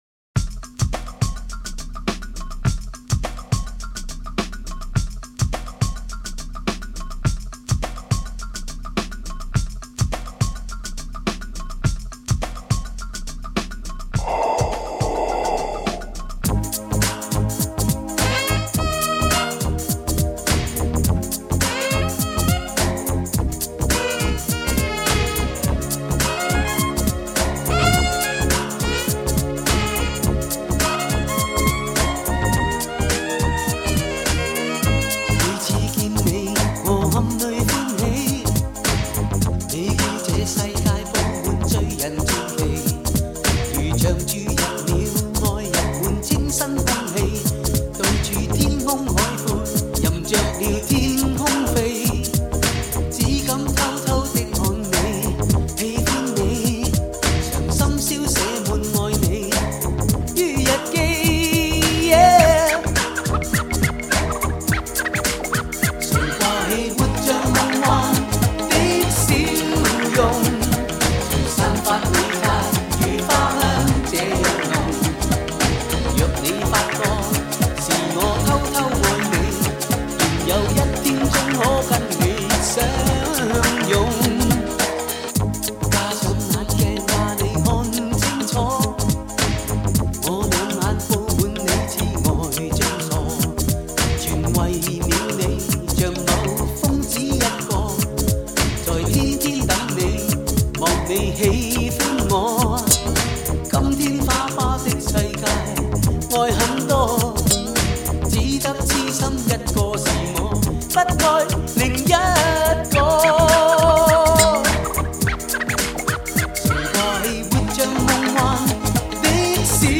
新鲜热辣的曲目重新编排混音